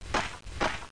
00041_Sound_gehen2.AIF